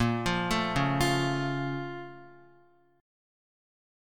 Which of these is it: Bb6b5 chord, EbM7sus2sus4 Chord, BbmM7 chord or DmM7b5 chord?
BbmM7 chord